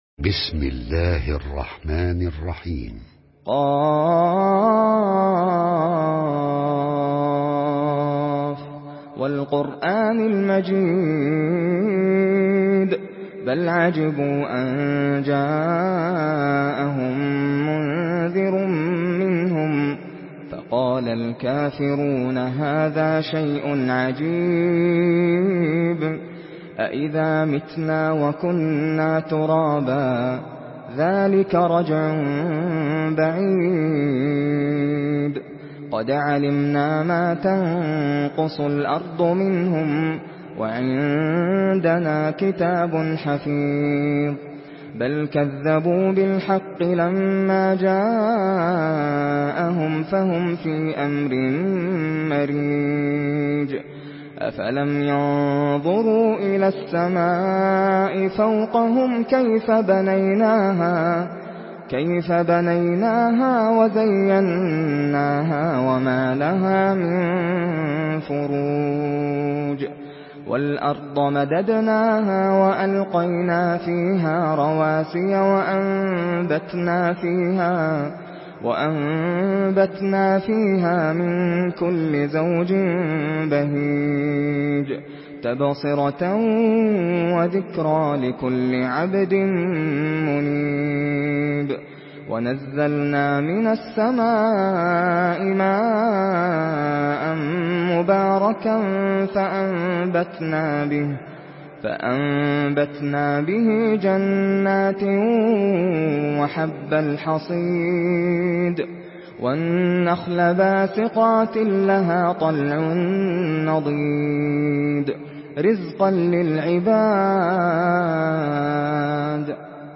Surah Kaf MP3 in the Voice of Nasser Al Qatami in Hafs Narration
Listen and download the full recitation in MP3 format via direct and fast links in multiple qualities to your mobile phone.